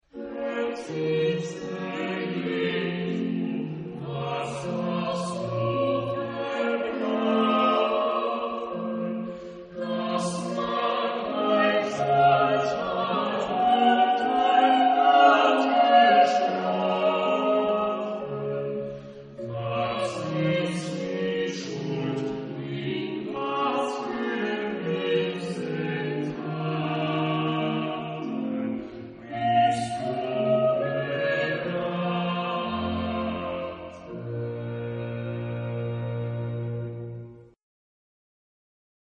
Genre-Style-Forme : Baroque ; Sacré ; Choral
Type de choeur : SATB  (4 voix mixtes )
Tonalité : si mineur